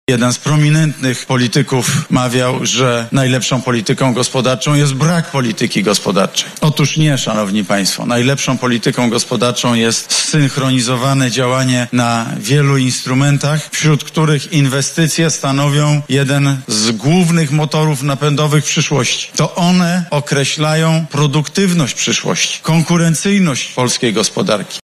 Mówimy o programach idących w dziesiątki miliardów złotych- powiedział podczas wizyty w Świdniku.
Podczas przemówienia Morawiecki podsumował ważne jego zdaniem problemy polskiej gospodarki ostatnich lat oraz przedstawił rozwiązania zawarte w Rządowym Funduszu Polski Ład.